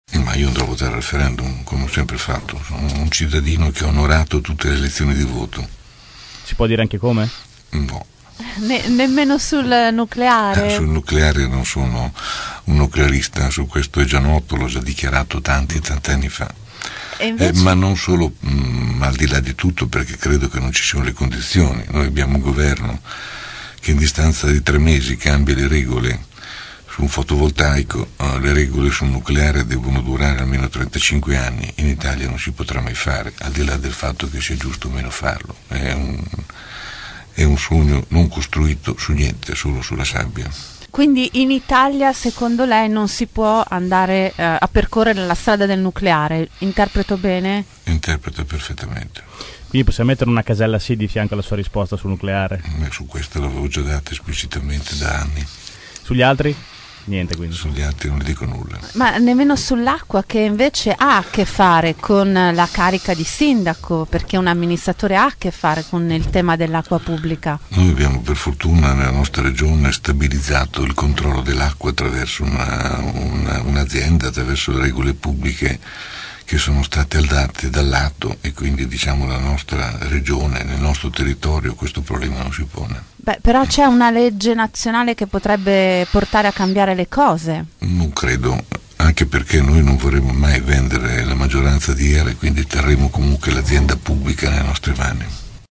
ospite questa mattina dei nostri studi.